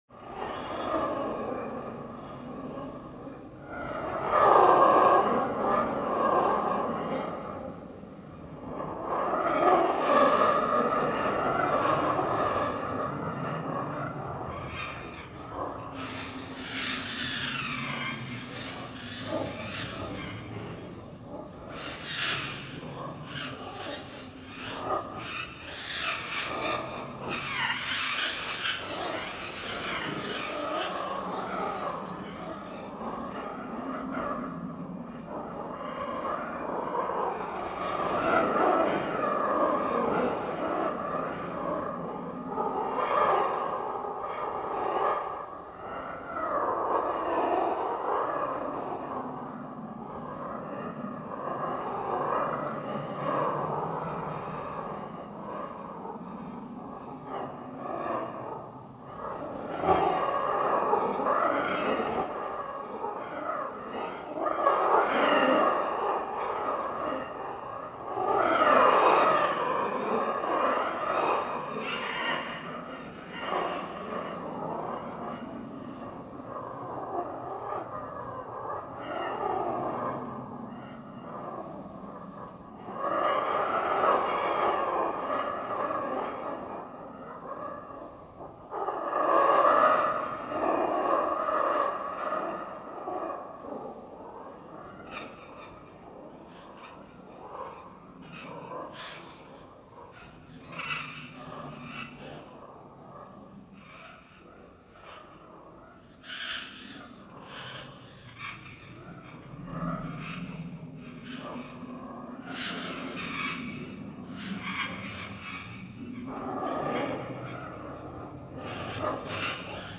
STRANGER-THINGS-The-Monster-Sound-Effect-DEMOGORGON.mp3